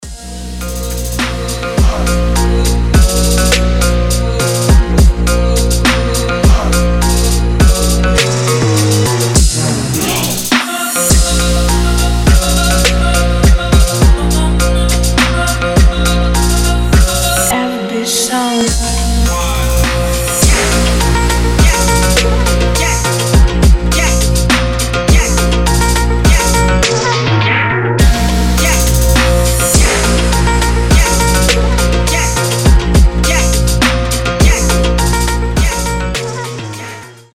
• Качество: 320, Stereo
басы
восточные
качающие
Крутой трэп с 8D звучанием где-то посередине)